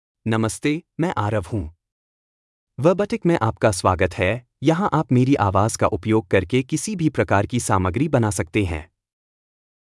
Aarav — Male Hindi (India) AI Voice | TTS, Voice Cloning & Video | Verbatik AI
Aarav is a male AI voice for Hindi (India).
Voice sample
Listen to Aarav's male Hindi voice.
Aarav delivers clear pronunciation with authentic India Hindi intonation, making your content sound professionally produced.